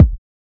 NewDopeSSOKickMixed.wav